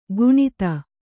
♪ guṇita